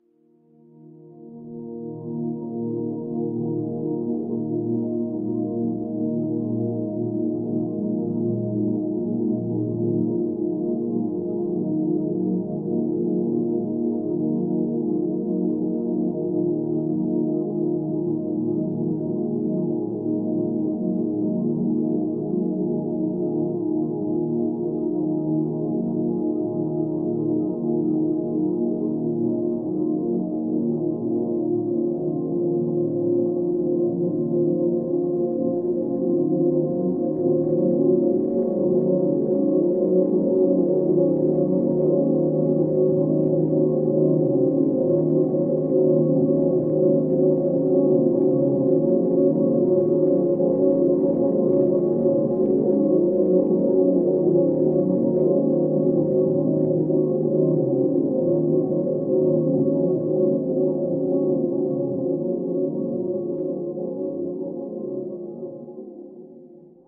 Meditation Calm